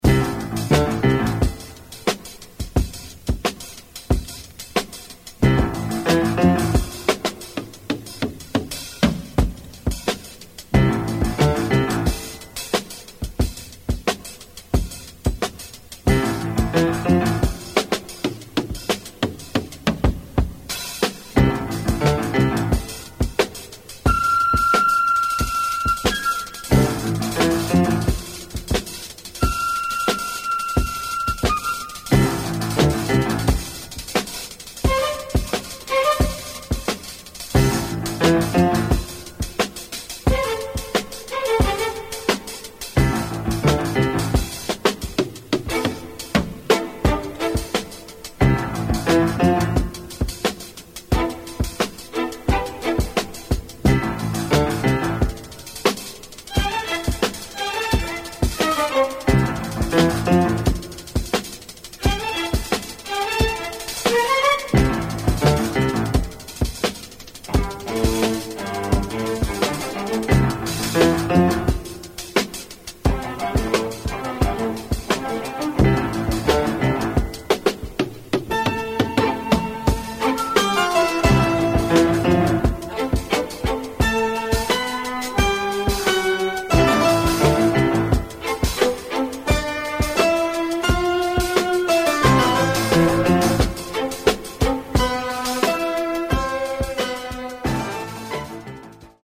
Italian scores for television